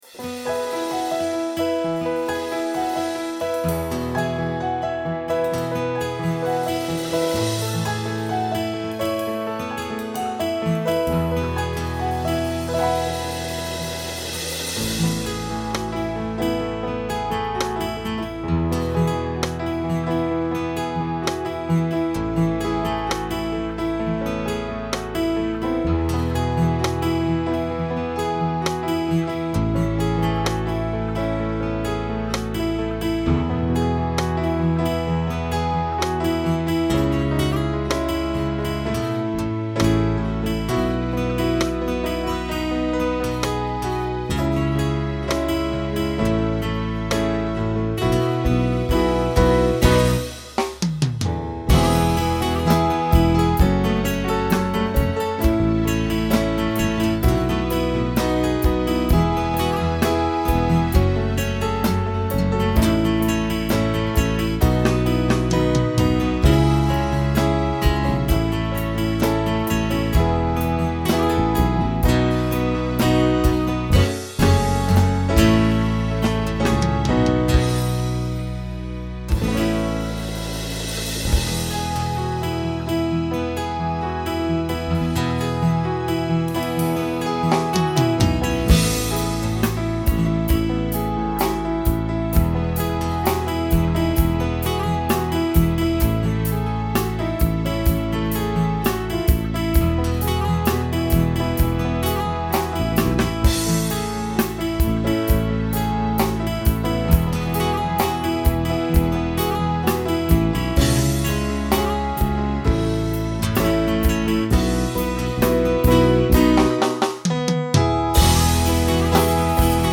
Voicing SATB Instrumental piano Genre Country
Ballad